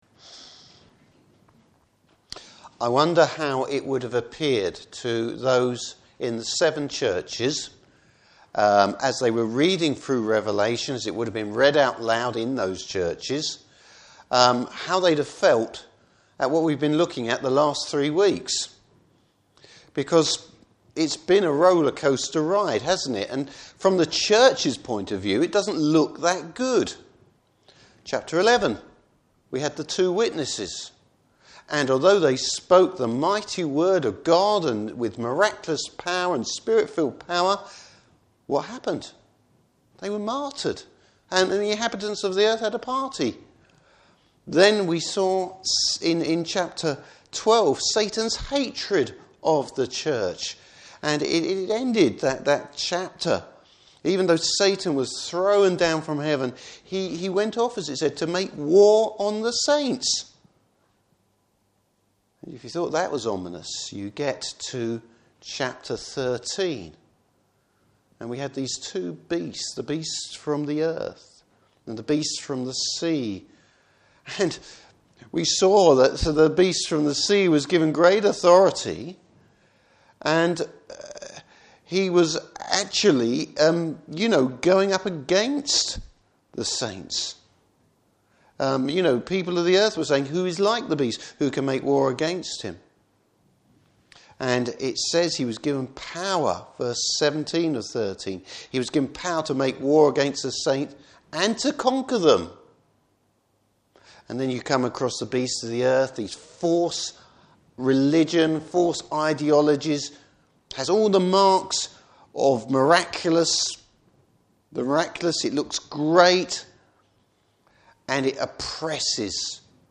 Service Type: Evening Service Bible Text: Revelation 14:1-13.